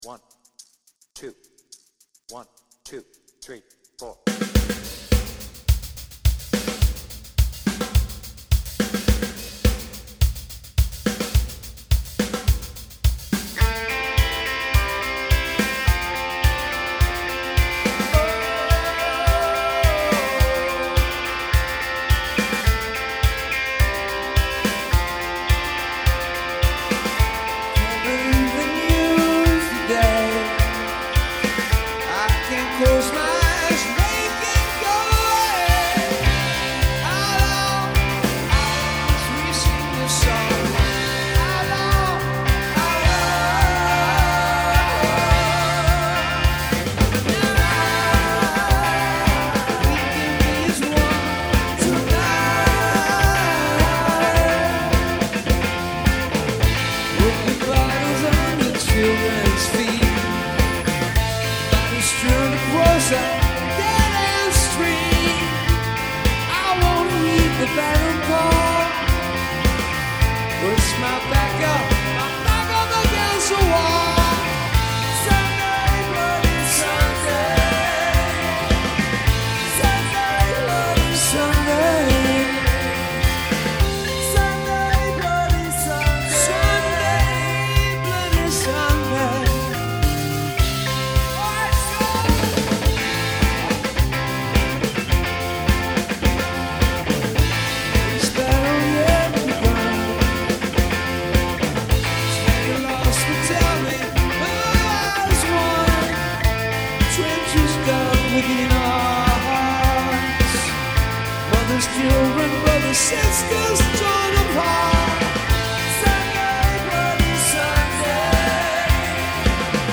BPM : 106
Tuning : Eb
Based on the 360° version + extended outro